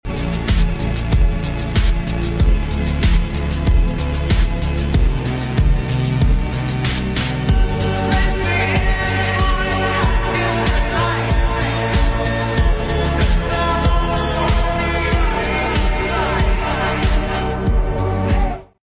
The moment the meteor in sound effects free download